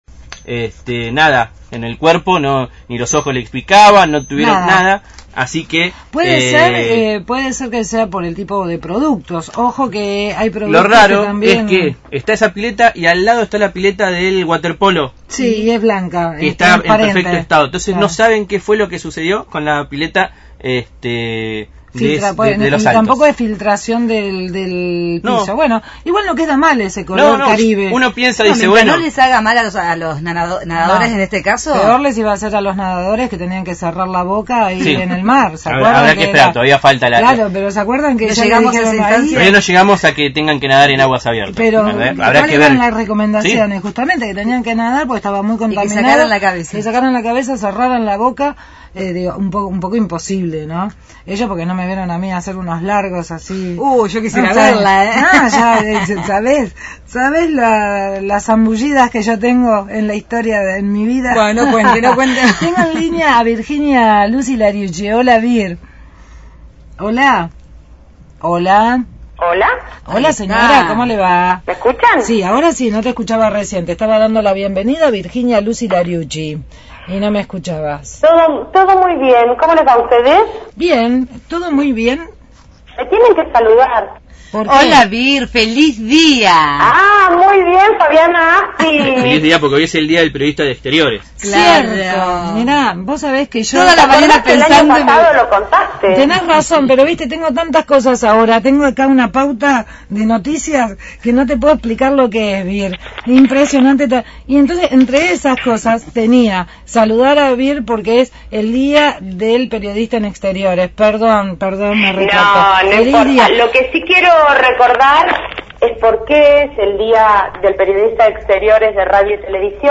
Móvil/ Concentración en Plaza Moreno por los Derechos de los Animales – Radio Universidad